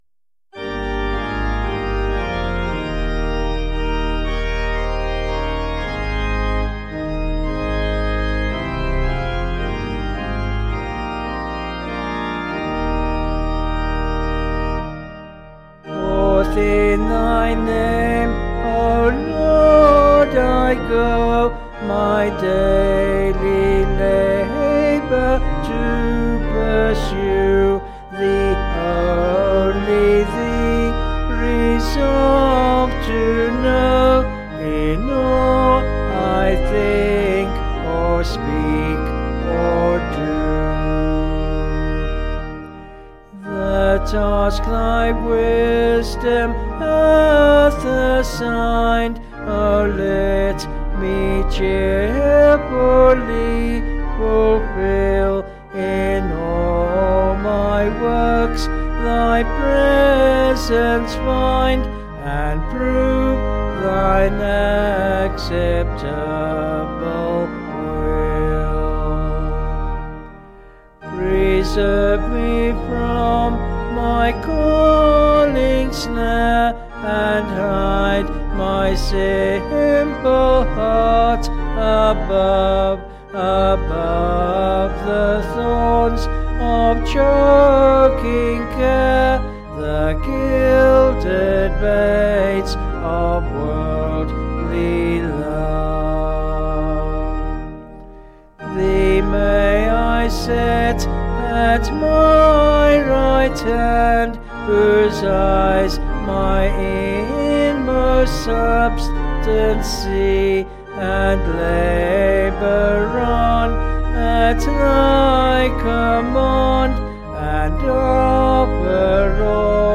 Vocals and Organ   263.7kb Sung Lyrics